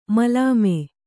♪ malāme